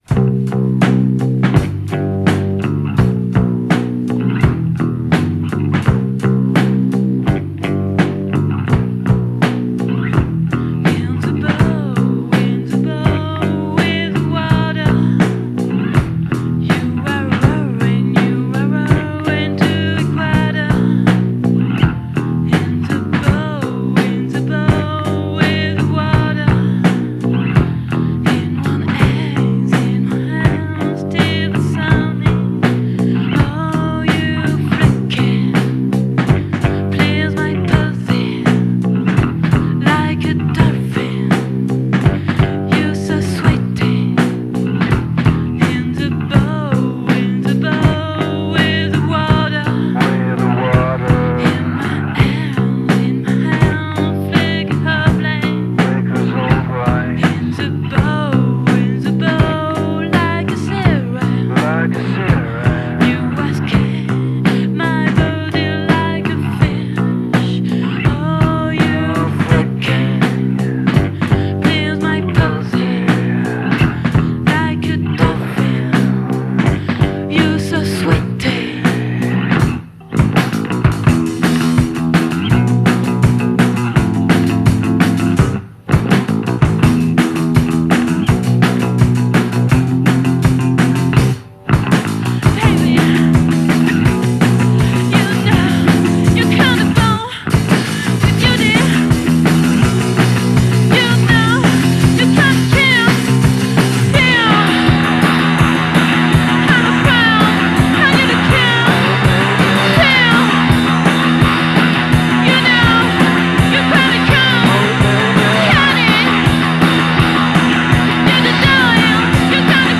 vocals
drums
both playing bass